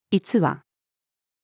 逸話-female.mp3